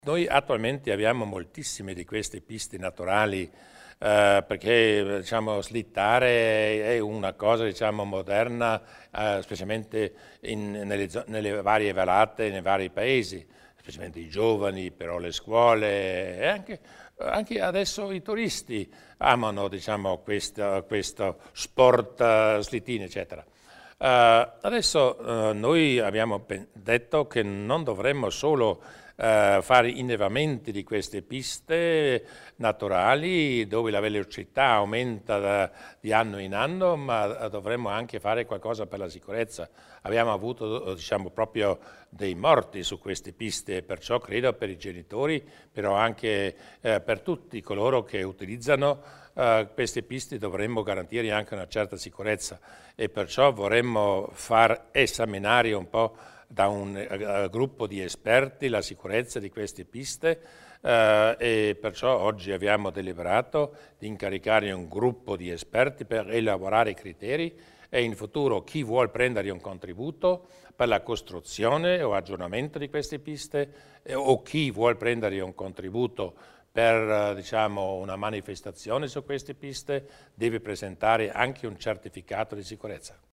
Il Presidente Durnwalder illustra gli interventi in tema di sicurezza sulle piste da slittino